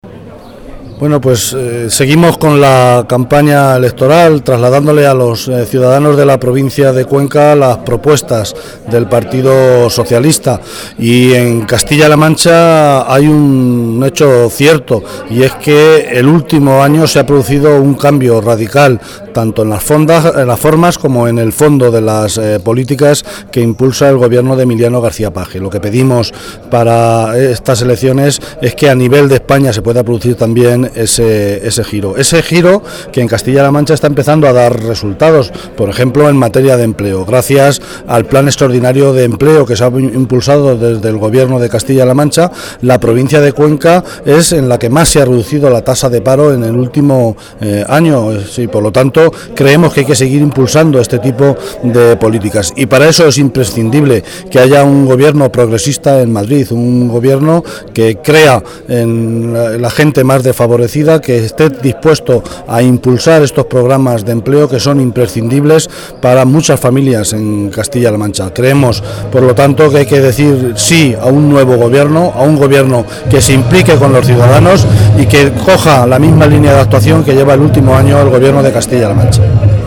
Martínez Guijarro en un acto comarcal en Mira
Cortes de audio de la rueda de prensa